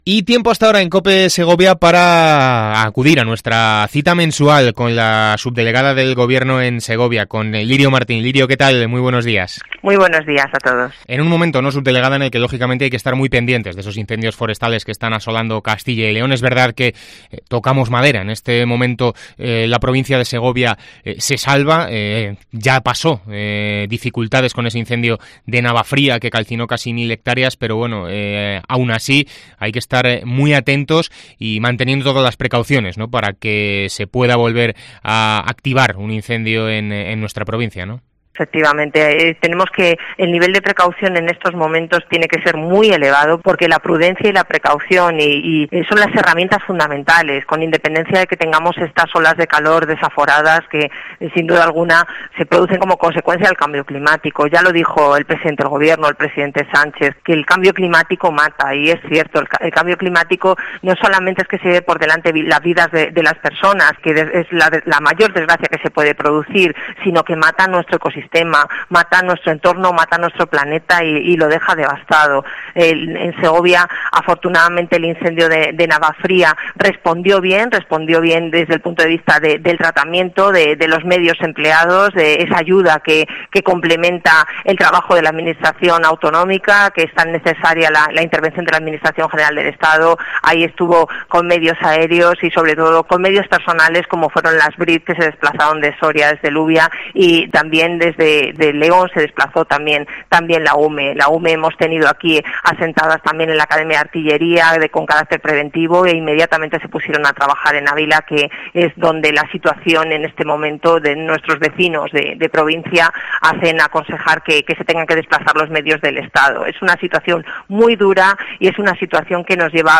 Entrevista con Lirio Martín, Subdelegada de gobierno en Segovia